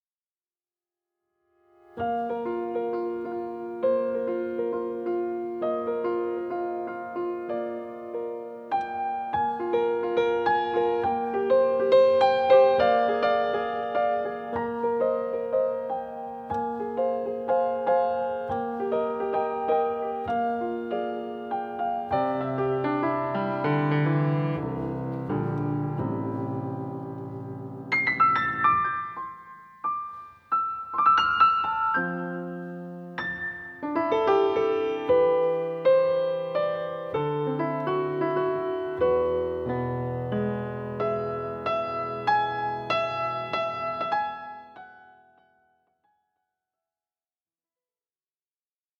Entre grégorien et gospel, le piano chante Marie.